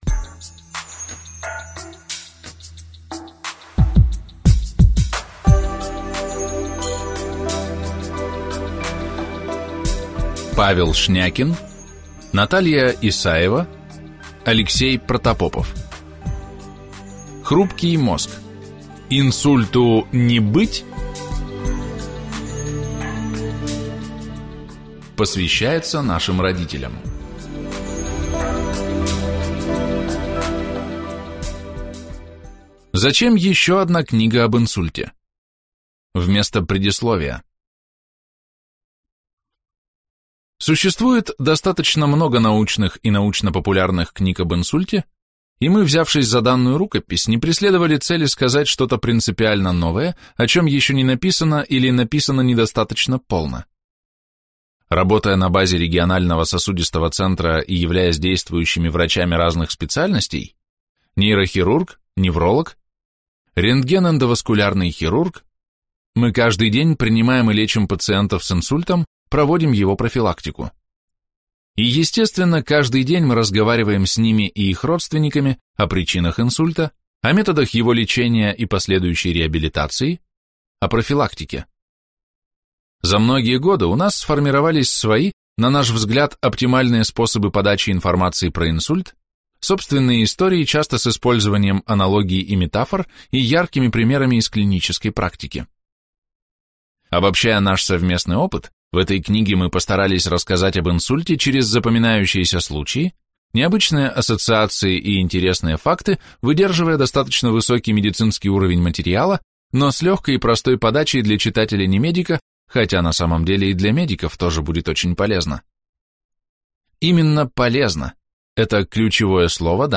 Аудиокнига Хрупкий мозг. Инсульту не быть?